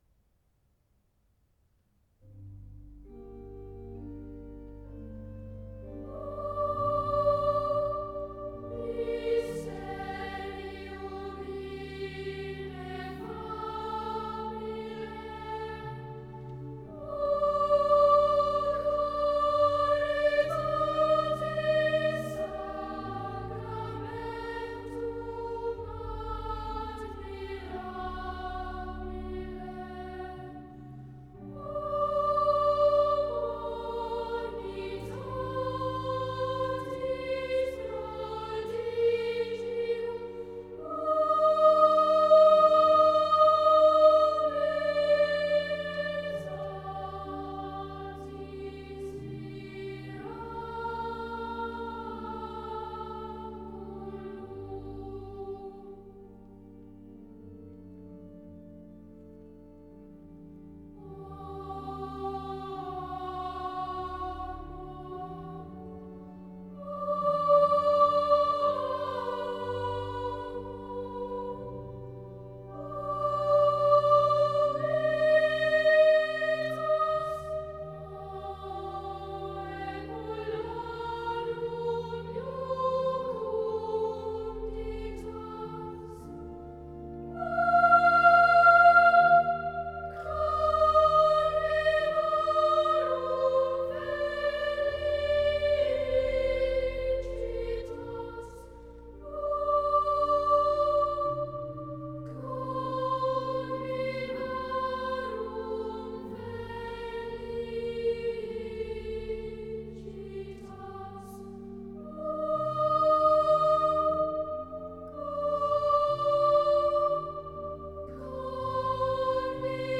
The challenge for Lent 2025 is to work out who is singing each day’s recording supplied by the Archive of Recorded Church Music and when it was made.
1997 Winchester College Quiristers & Cathedral Choristers (dir.